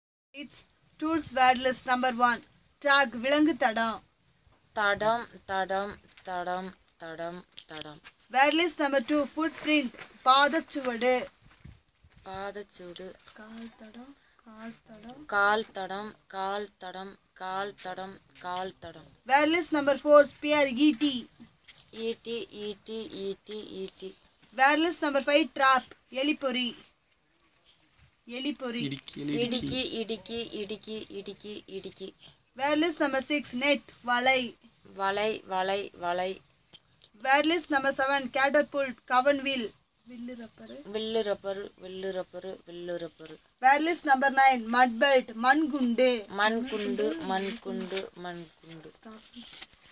Elicitation of words about hunting, fishing and its tools - Part 2